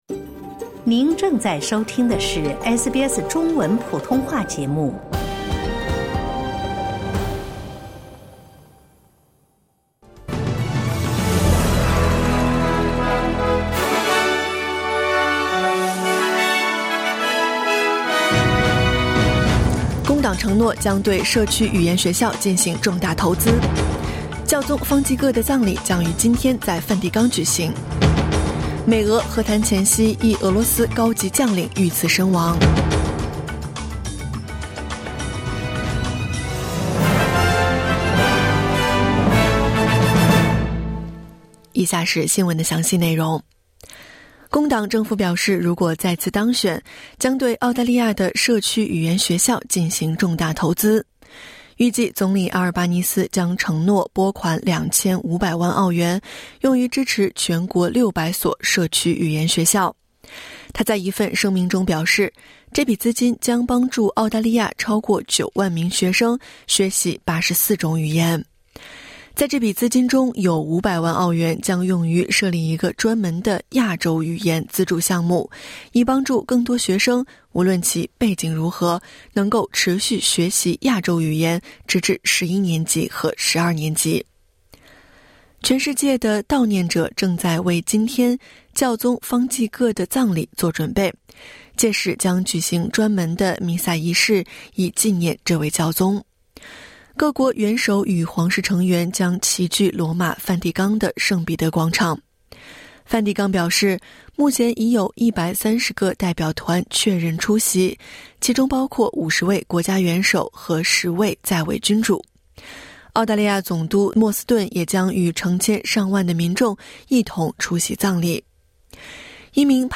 SBS早新闻（2025年4月26日）